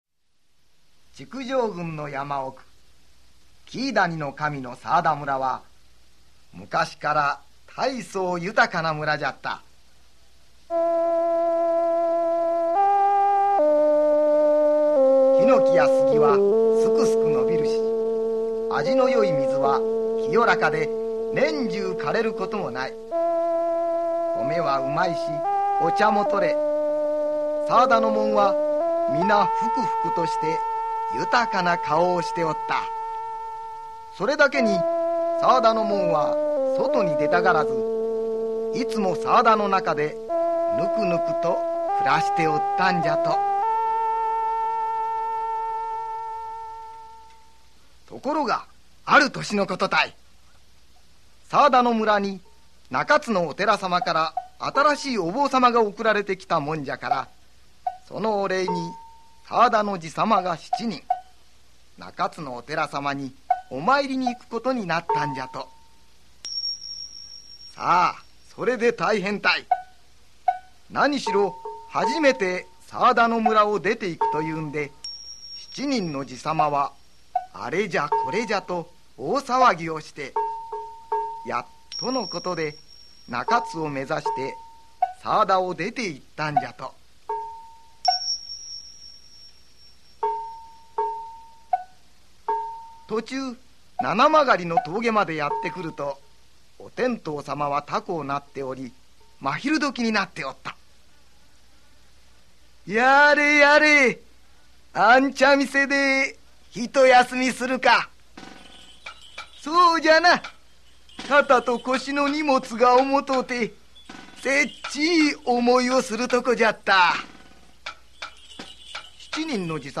[オーディオブック] 寒田のじさまが寺まいり